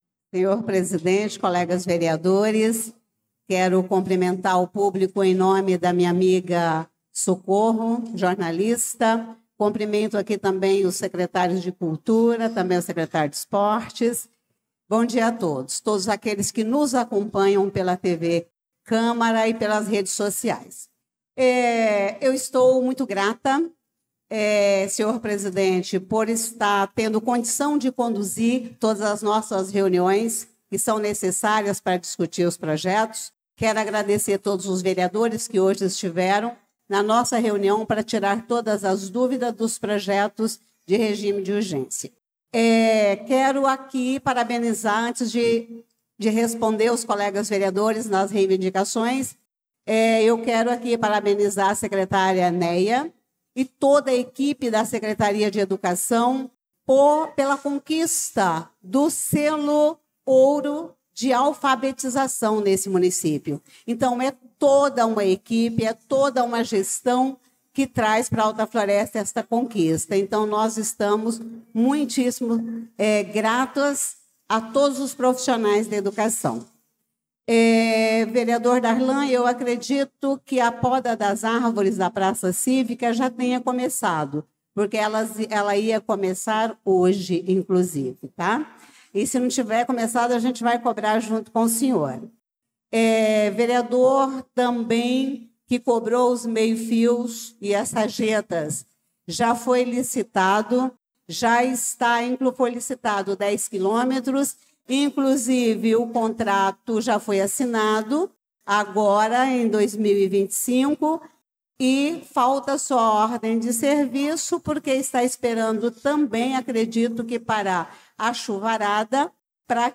Pronunciamento da Vereadora Elisa Gomes na Sessão Ordinária do dia 11/02/2025